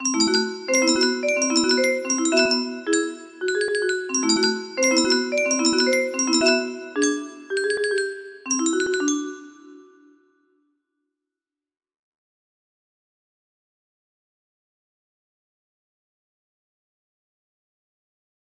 Claro de Luna nº 14 music box melody